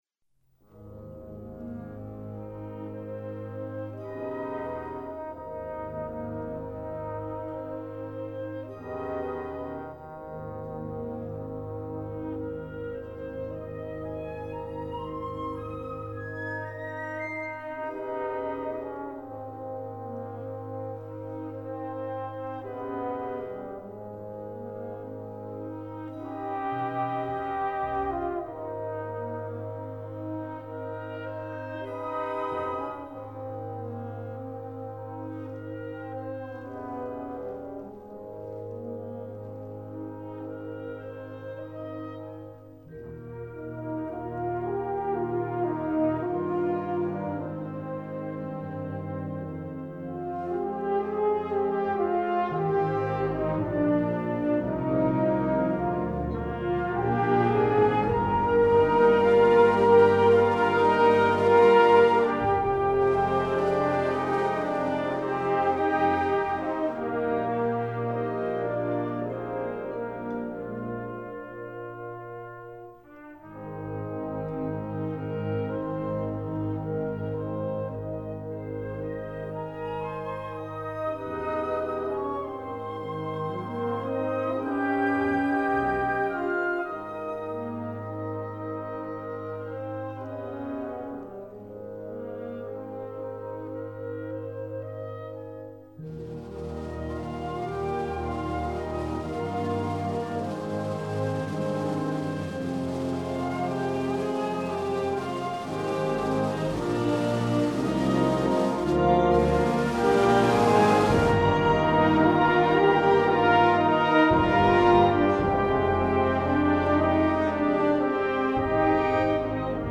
classical, opera